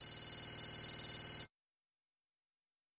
Звук печатающихся букв при отображении локации базы